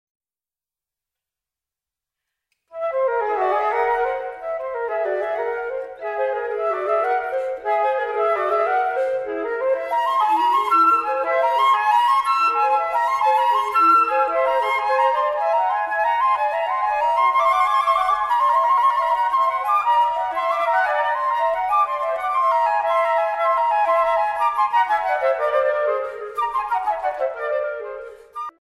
Flute:
six duets for two flutes